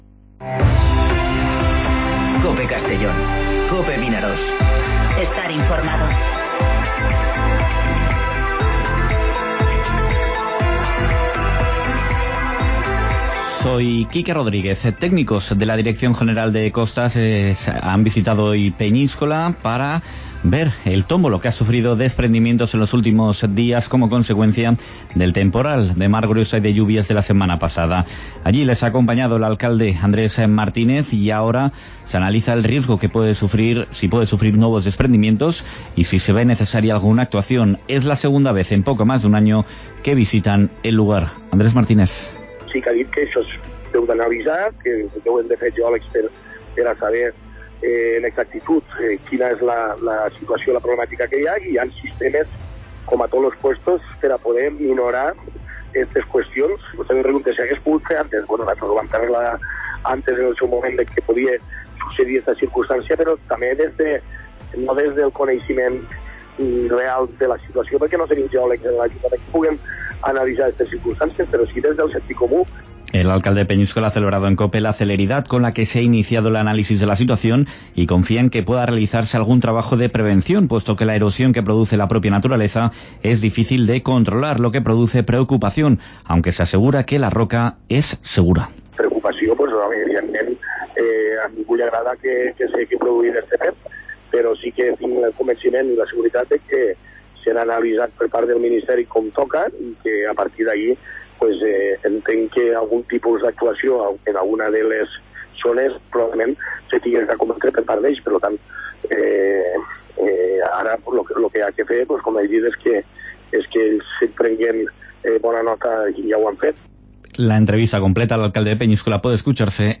Informativo Mediodía COPE en Castellón (09/12/2019)